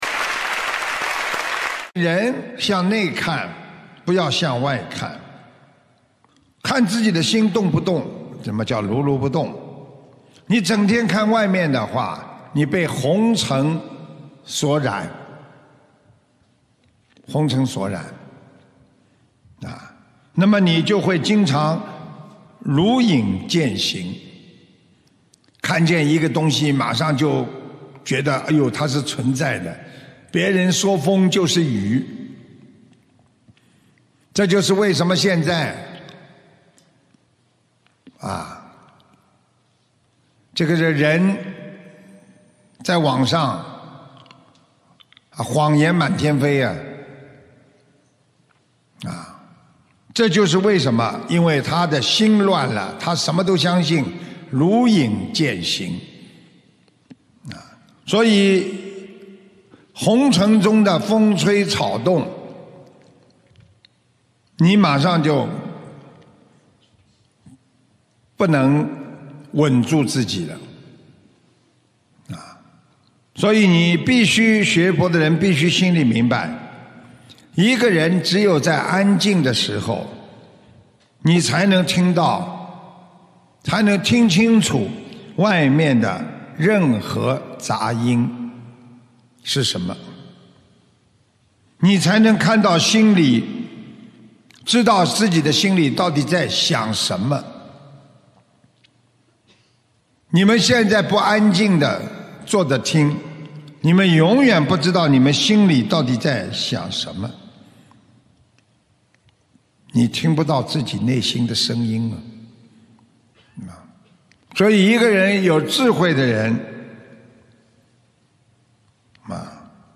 首页 >>佛法书籍 >> 广播讲座